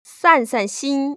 [sàn‧san xīn] 싼산신